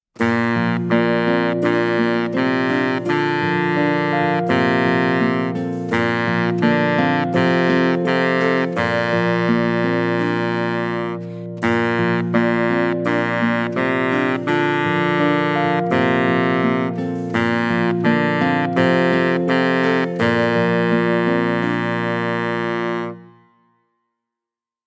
Voicing: Baritone Saxophone